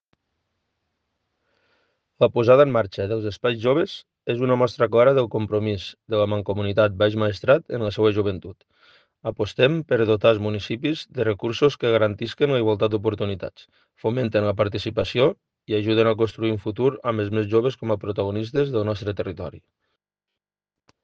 El president de la Mancomunitat Baix Maestrat, Juan José Cabanes Ramón, ha valorat positivament la iniciativa i ha destacat que:
Tall-de-veu-NP-president-mancomunitat.mp3